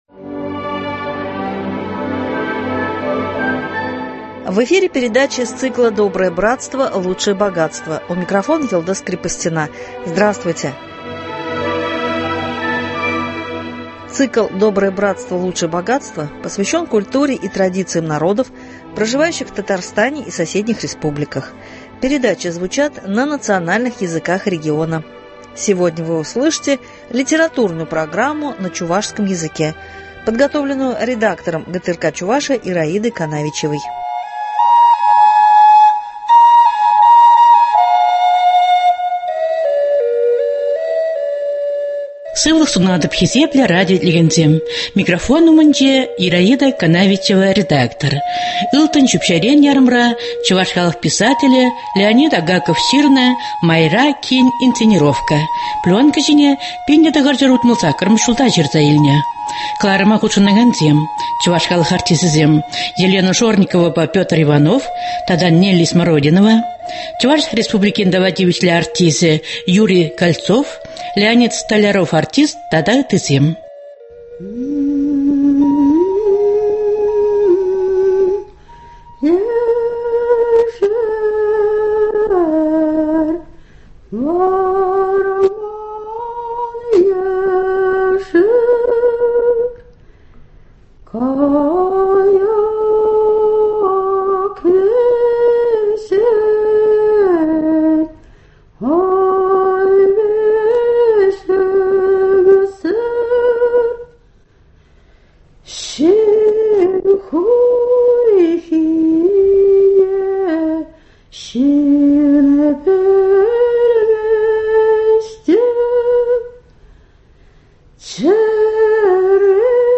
Сегодня вы услышите литературную передачу на чувашском языке